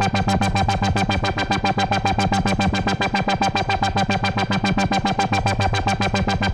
Index of /musicradar/dystopian-drone-samples/Tempo Loops/110bpm
DD_TempoDroneA_110-G.wav